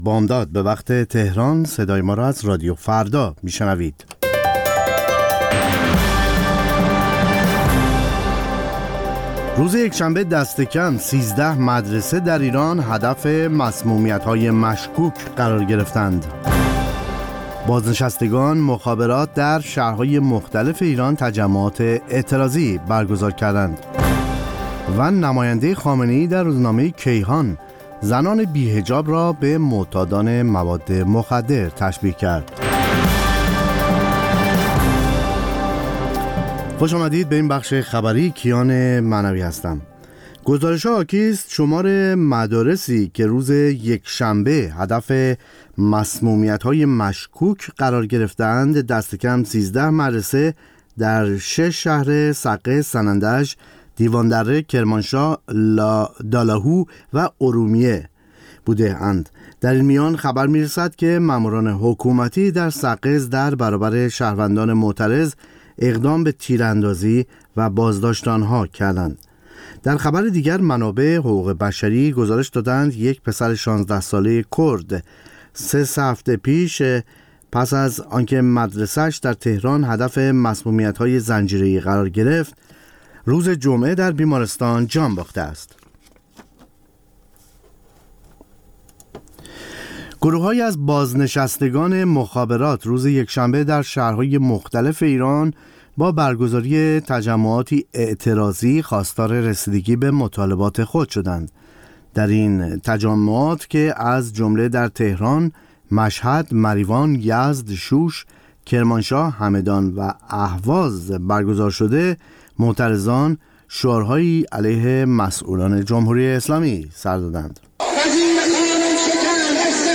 سرخط خبرها ۲:۰۰